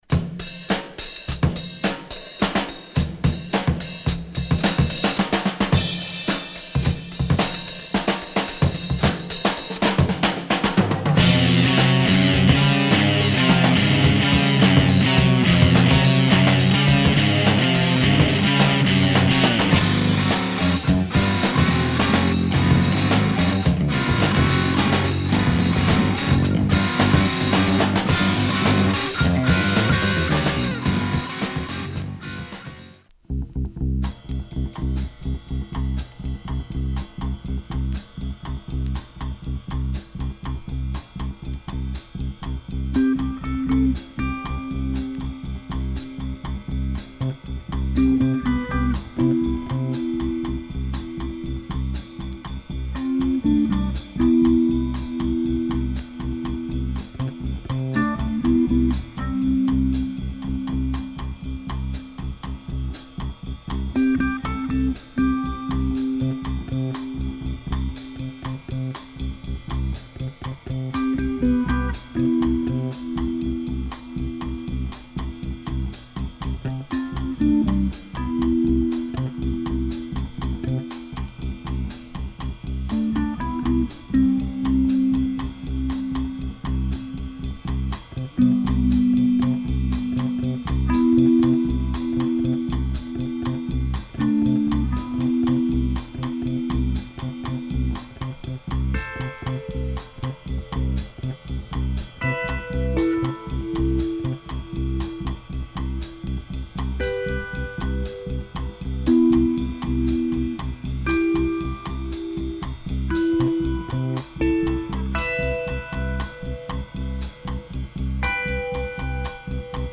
Underground Aussie prog group